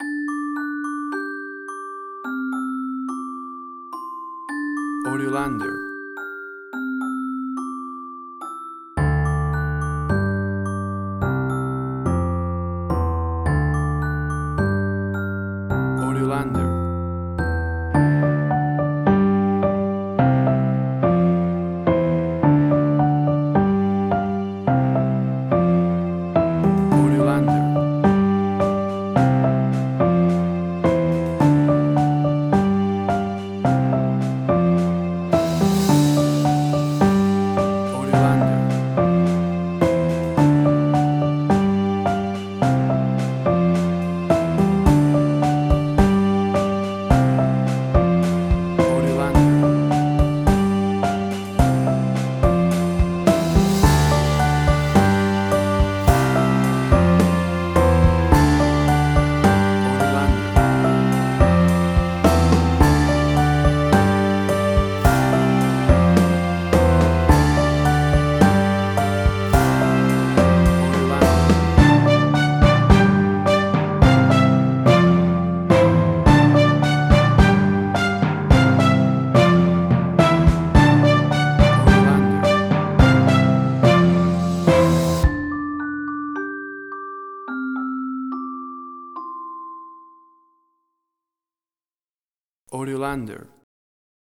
Indie Quirky.
Tempo (BPM): 108